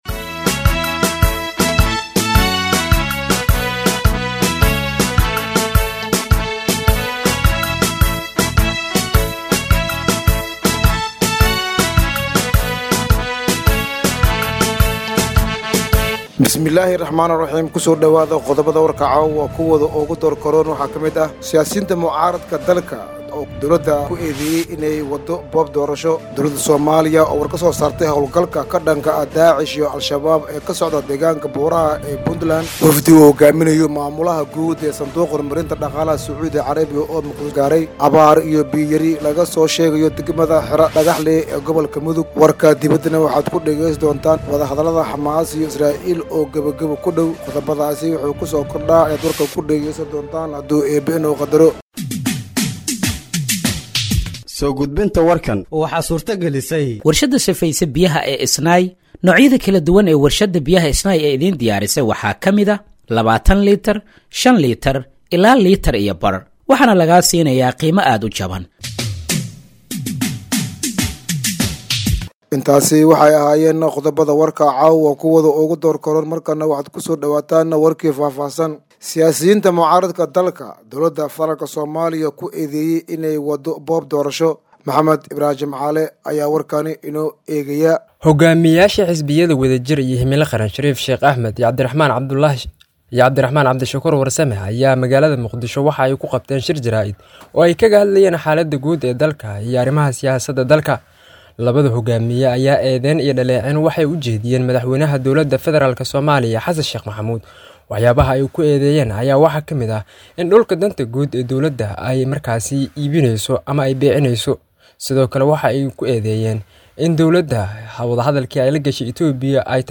Dhageeyso Warka Habeenimo ee Radiojowhar 14/01/2025
Halkaan Hoose ka Dhageeyso Warka Habeenimo ee Radiojowhar